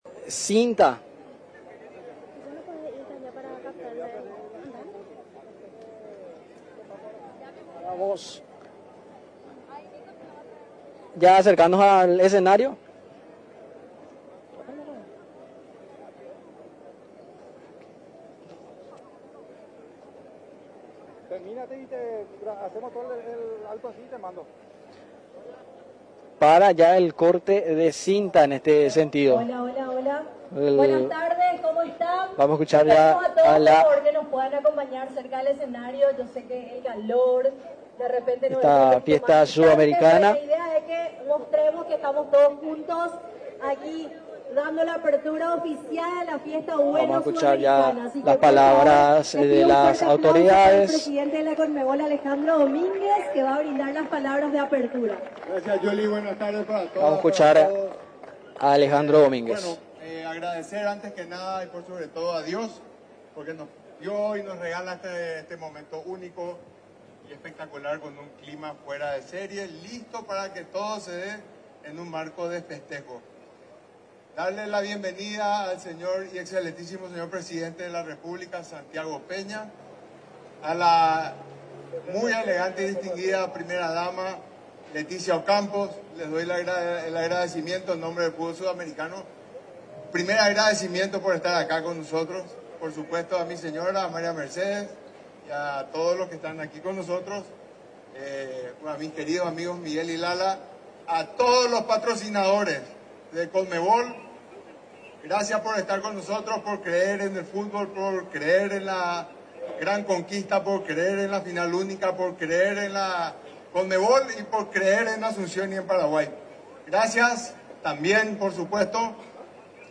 El presidente de la CONMEBOL, Alejandro Domínguez, durante el acto de habilitación de la FAN FEST, ubicado en la Costanera de Asunción, explicó los detalles sobre el protocolo institucional que habilitó la Final Única en Asunción.